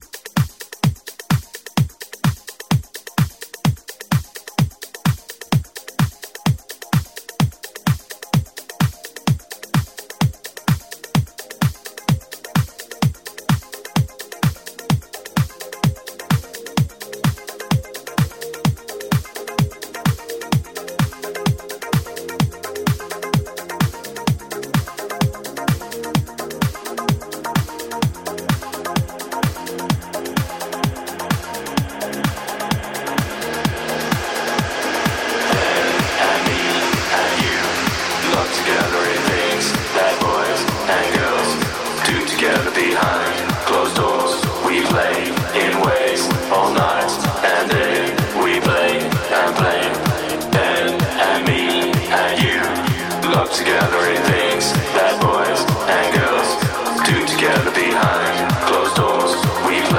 Электронная
Шестой выпуск сборника клубной мgузыки Екатеринбурга.